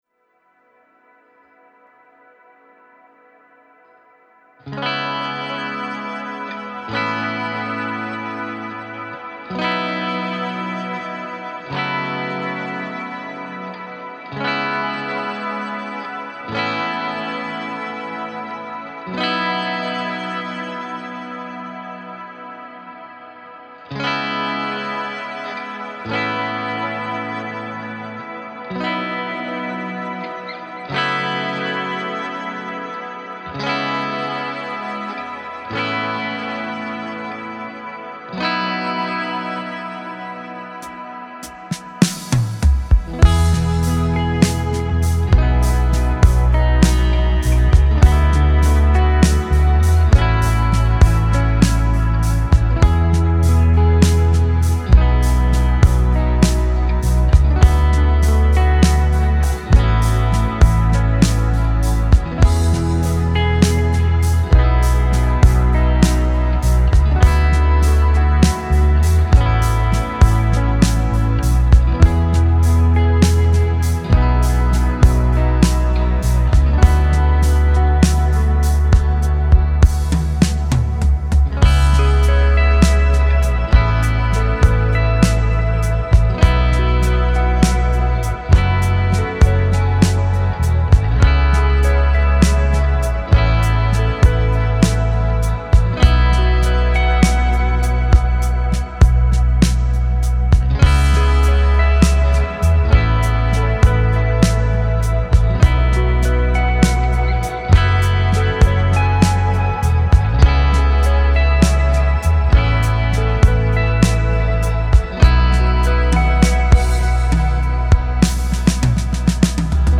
Immerse Yourself in Lush Ambient Guitar Tones — Fractal
Backing Track:
All recorded into Logic.
No post processing.